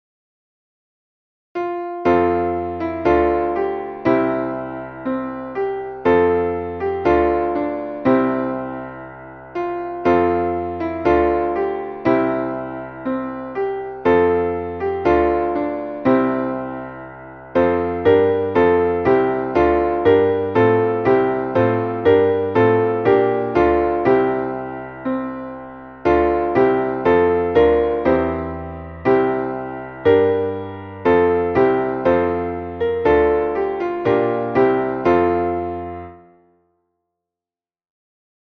Traditionelles Kirchen-/ Pfingstlied